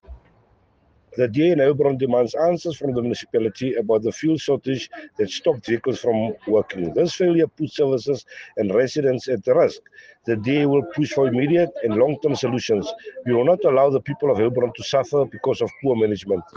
Afrikaans soundbites by Cllr Robert Ferendale and Sesotho soundbite by Cllr Joseph Mbele.